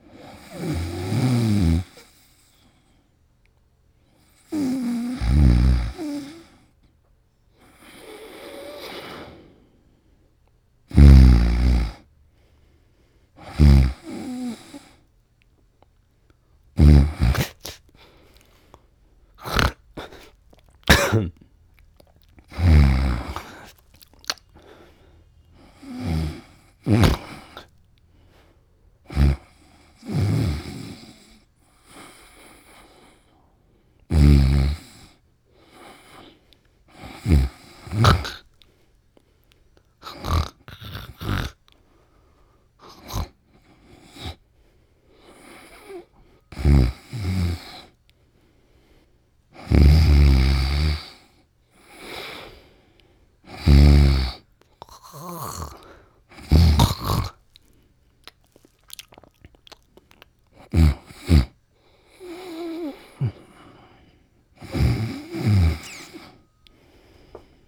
Male Snores - Various snores - Close Up
asleep breath breathing cartoon funny human light loud sound effect free sound royalty free Funny